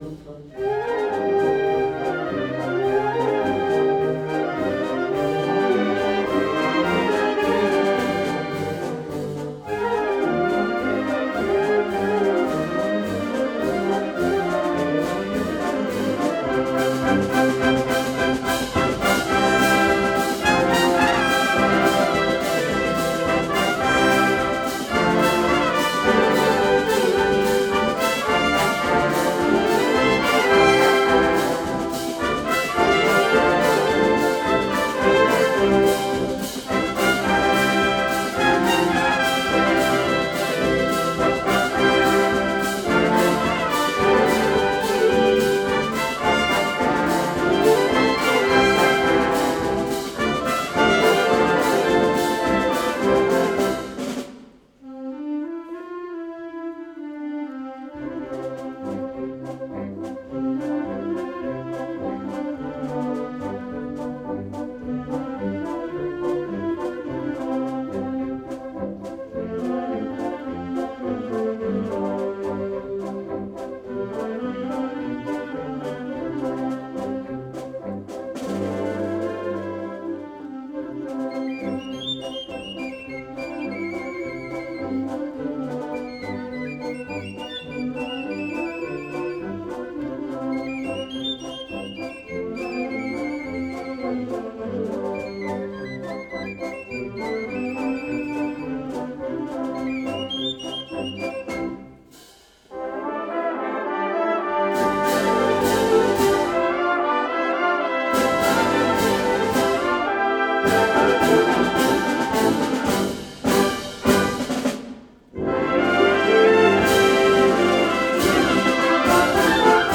Himne
himno_plaça_del_forn.mp3